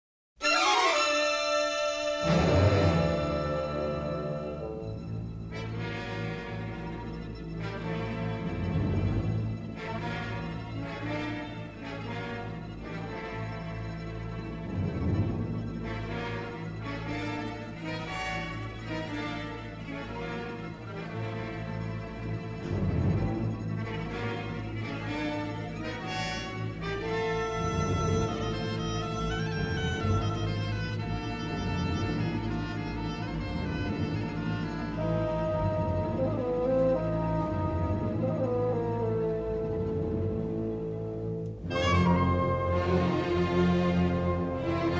with harmonic-minor twists both sinister and mysterious.